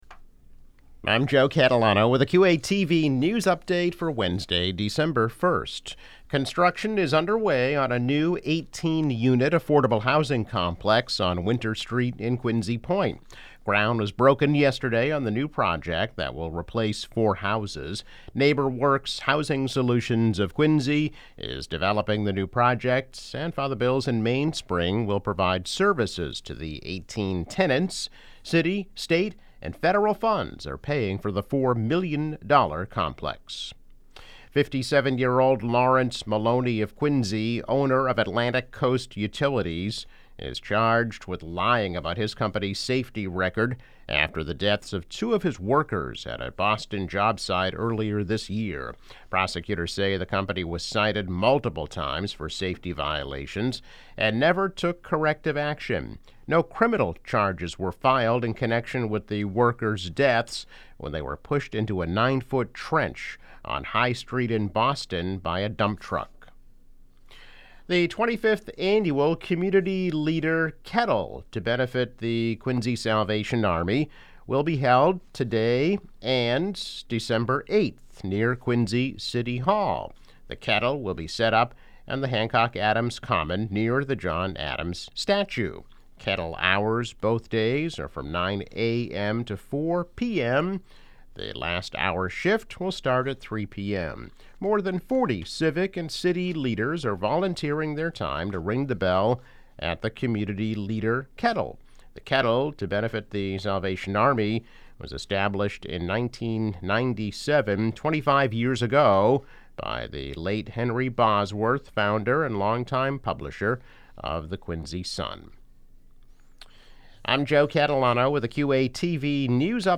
News Update - December 1, 2021